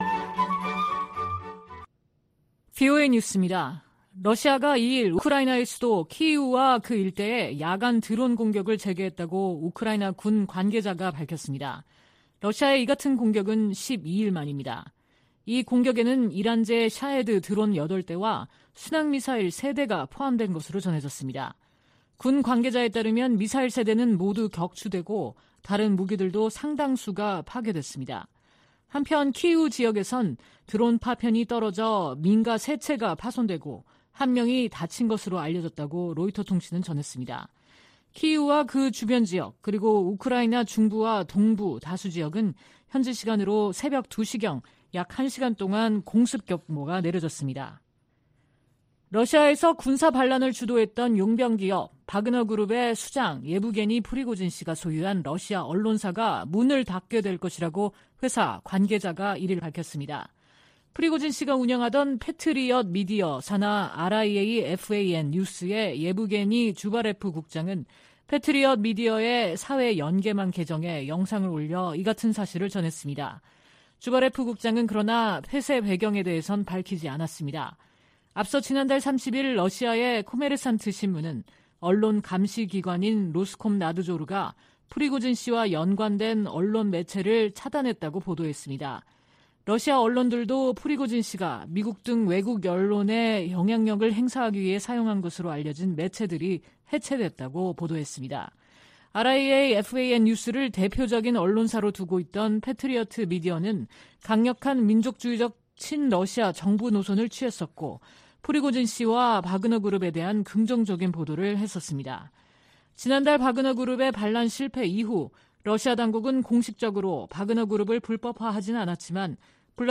VOA 한국어 방송의 일요일 오후 프로그램 3부입니다. 한반도 시간 오후 10:00 부터 11:00 까지 방송됩니다.